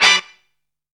BLASTER HIT.wav